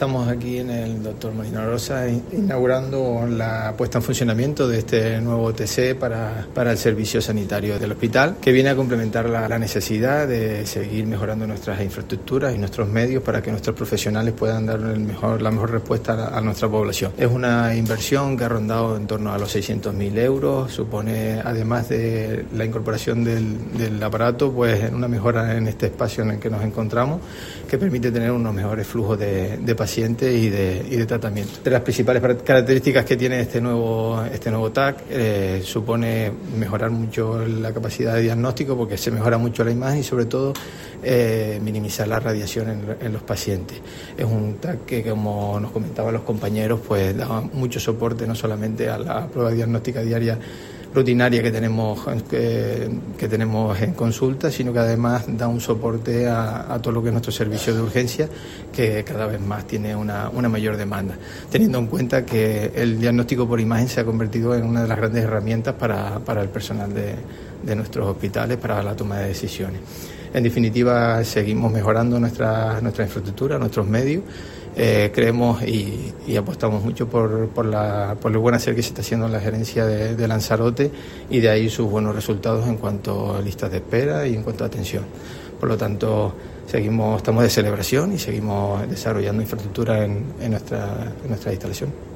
Declaraciones del director del Servicio Canario de la Salud, Adasat Goya:
Adasat-Goya-sobre-nuevo-TAC-del-Hospital-Molina-Orosa.mp3